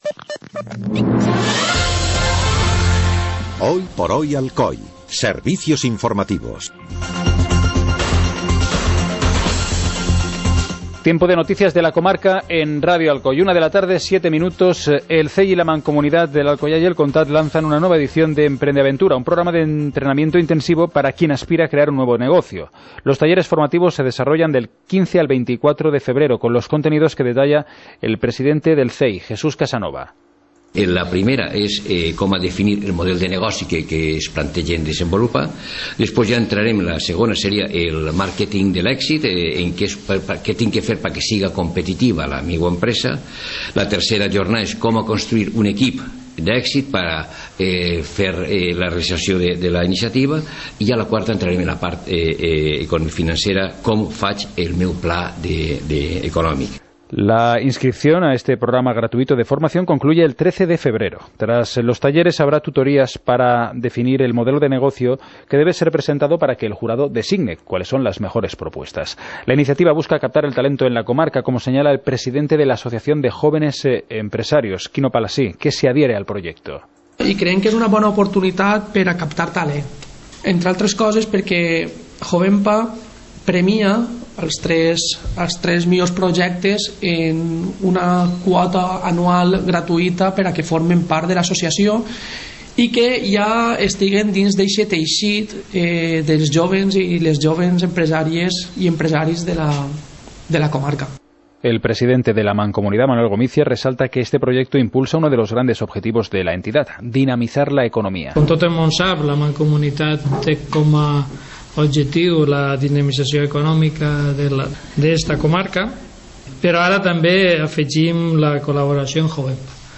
Informativo comarcal - jueves, 02 de febrero de 2017